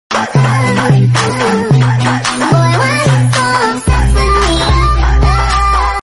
BARK BARK WOOF SNARL GRWOEL sound effects free download